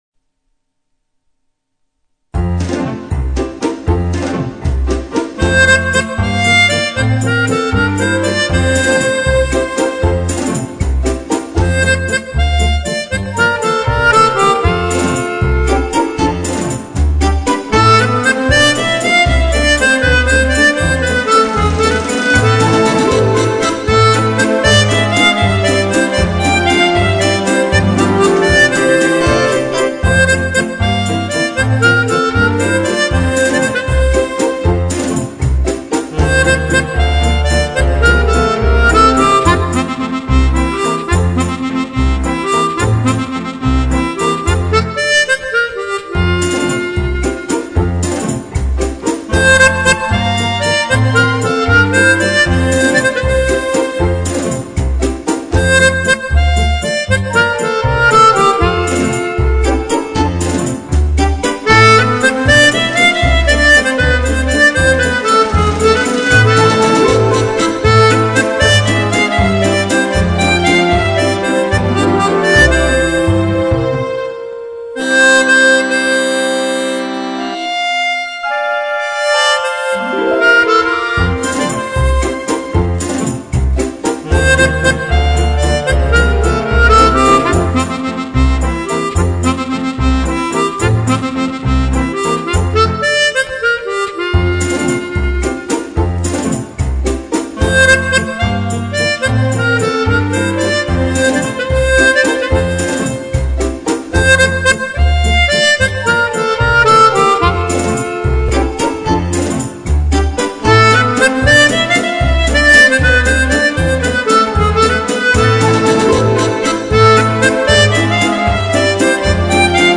Tonalité Fa majeur